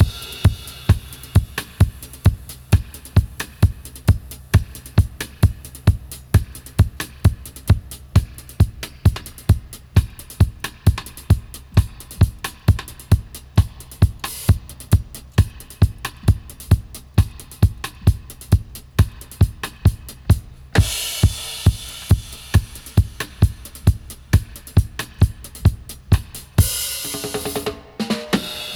134-FX-04.wav